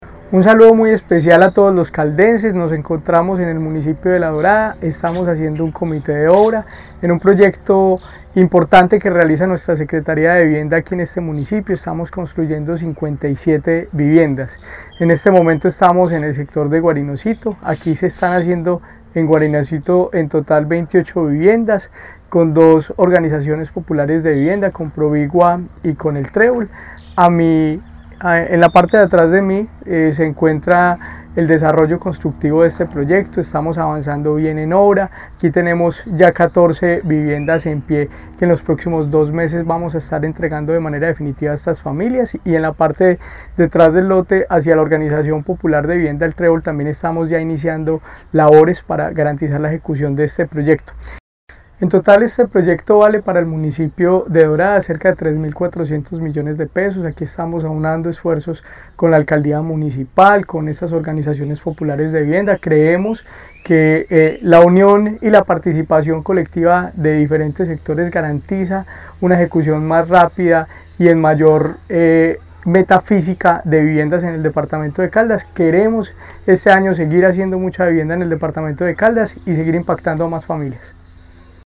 Jorge William Ruiz Ospina – Secretario de Vivienda y Territorio del departamento.
JORGE-WILLIAM-RUIZ-OSPINA-SECRETARIO-VIVIENDA-CALDAS-AVANCES-OBRAS-VIVIENDAS-GUARINOCITO_01.mp3